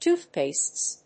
/ˈtuˌθpests(米国英語), ˈtu:ˌθpeɪsts(英国英語)/